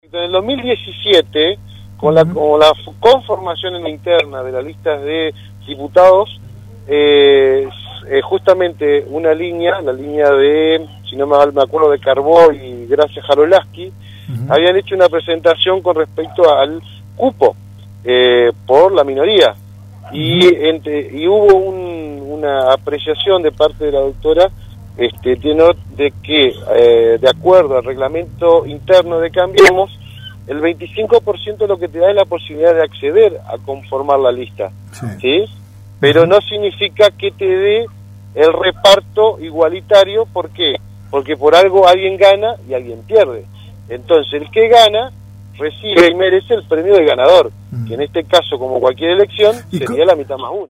El concejal Luis Díaz, que integra la lista de candidatos a ediles de Sergio Varisco en el séptimo lugar, dialogó con Radio RD 99.1 acerca de la polémica por la conformación definitiva del elenco que acompañará la candidatura del intendente a la reelección: